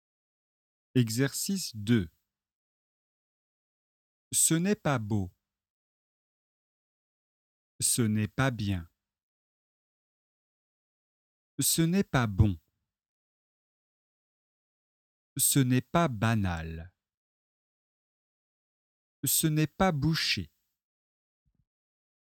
Leçon de phonétique et de prononciation, niveau débutant (A1).
Écoutez et répétez.